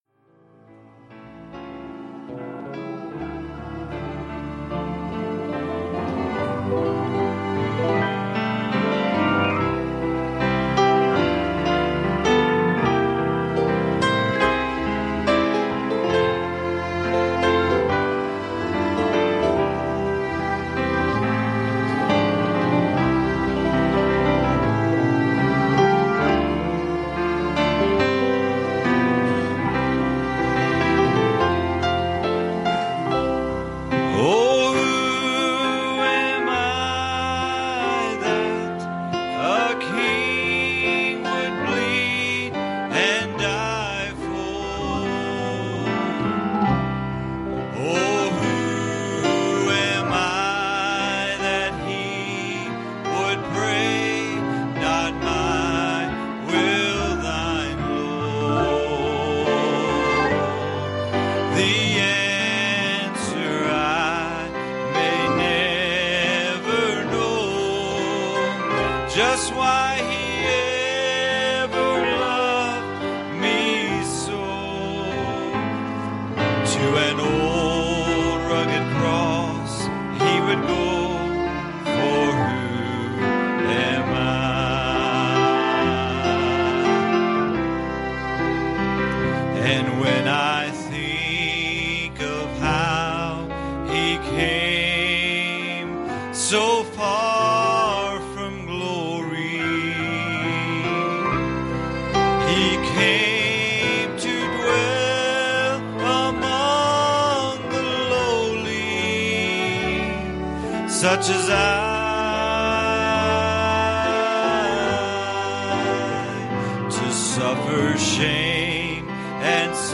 The Congregation Passage: Joel 2:25 Service Type: Sunday Morning "Watch.